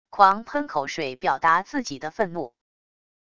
狂喷口水表达自己的愤怒wav音频